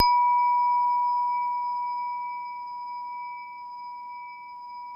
WHINE  B3 -R.wav